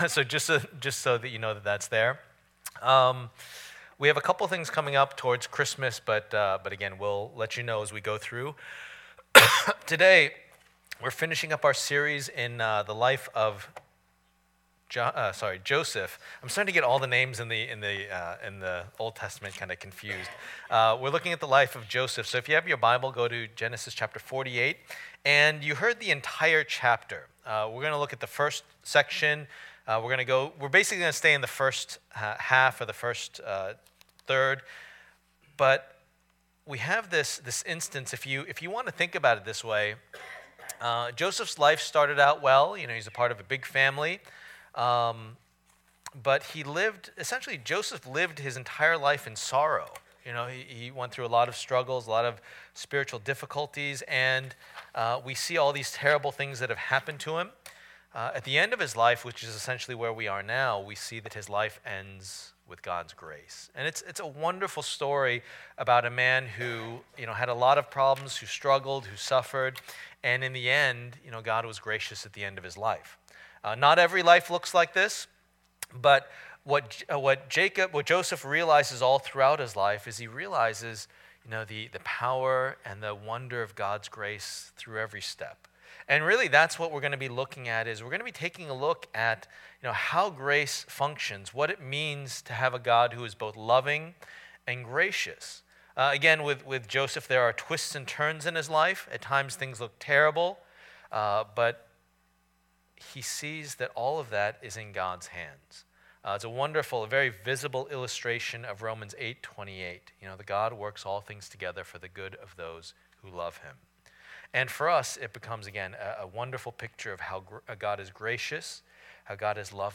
Passage: Genesis 48:1-22 Service Type: Lord's Day